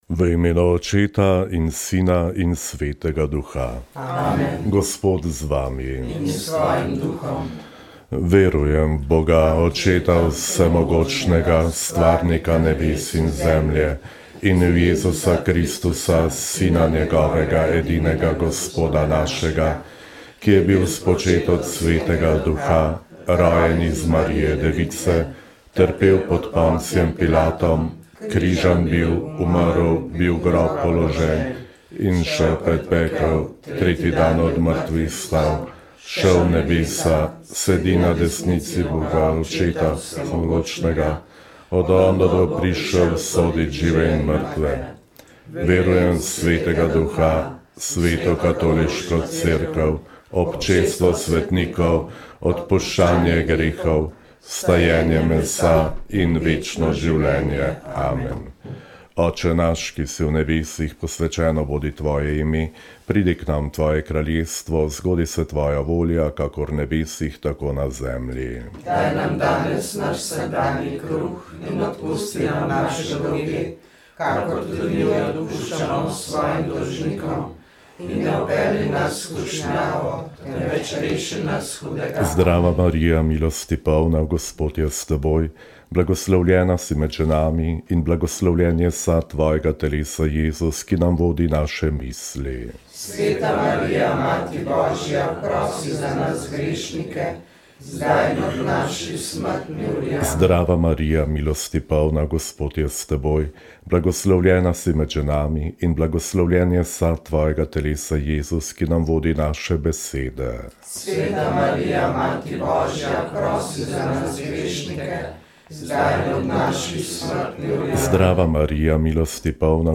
Pogovor s kardinalom Vinkom Bokaličem Igličem